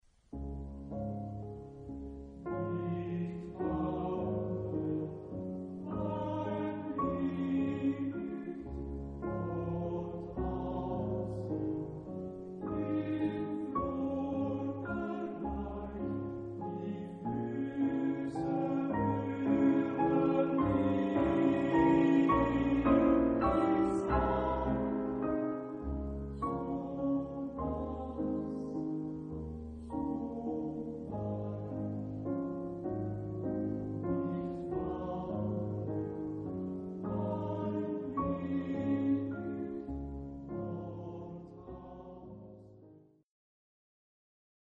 Romántico. Vals viénes.. ciclo.